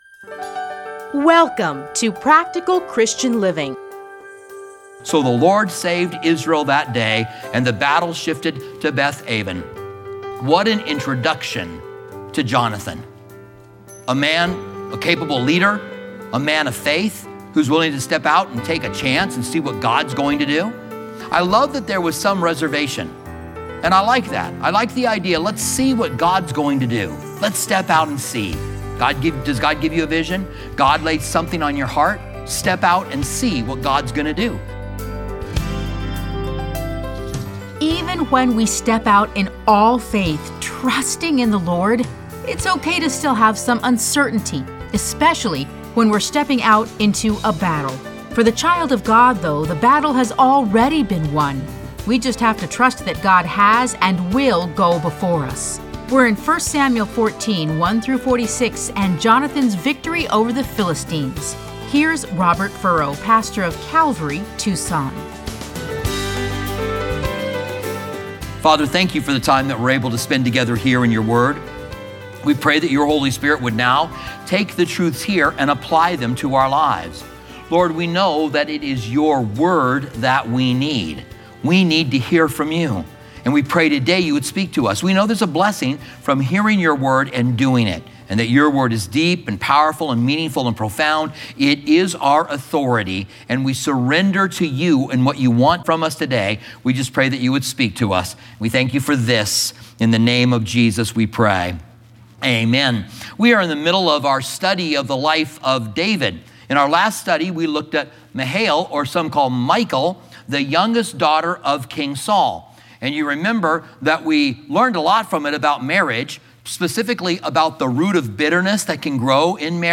Listen to a teaching from 1 Samuel 14:1-46.